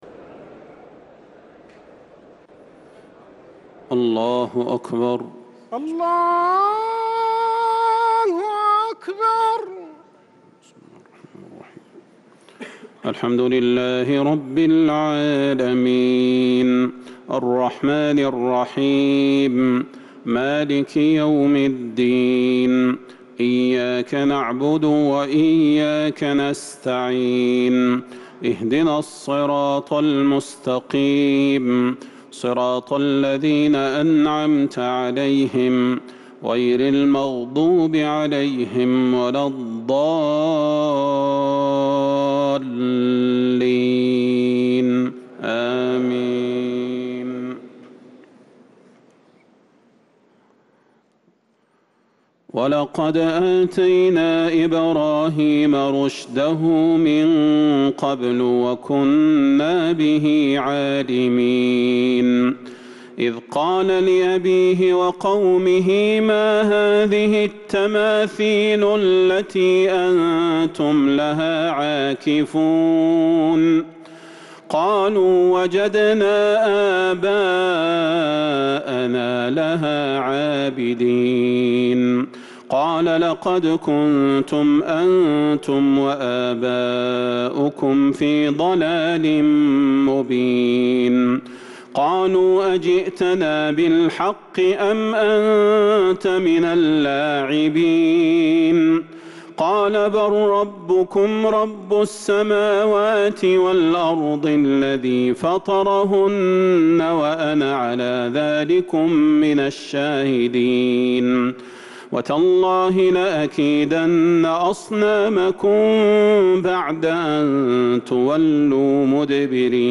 صلاة التراويح ليلة 21 رمضان 1443 للقارئ صلاح البدير - التسليمتان الاخيرتان صلاة التهجد